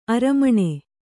♪ aramaṇe